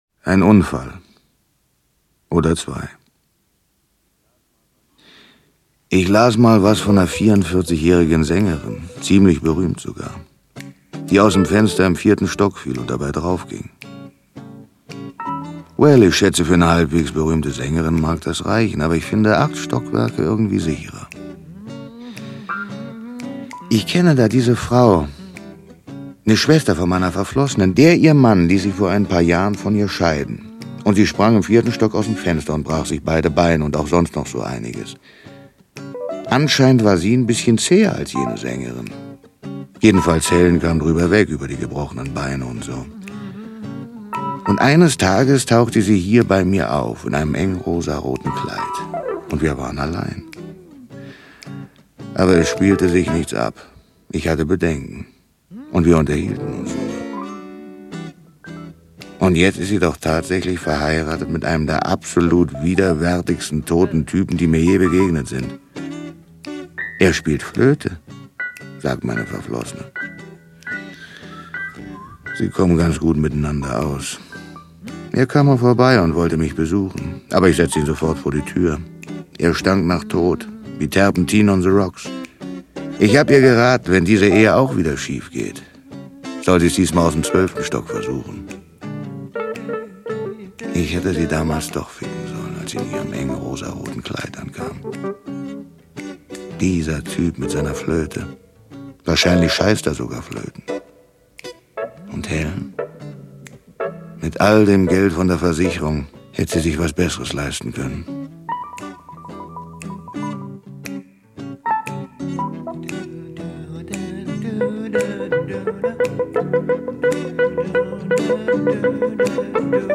Götz George liest Charles Bukowski - Charles Bukowski - Hörbuch